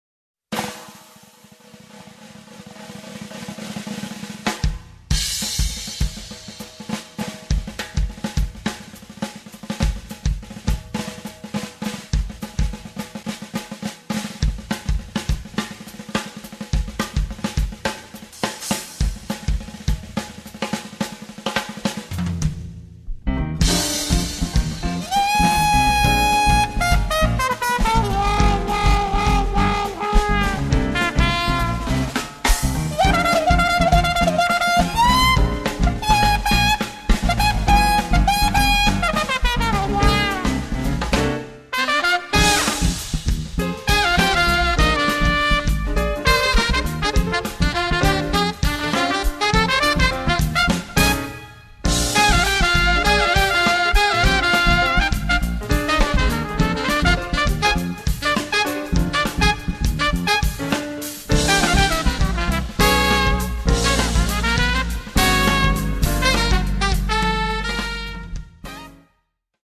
tromba e flicorno
sax tenore, alto e soprano
pianoforte
basso elettrico
batteria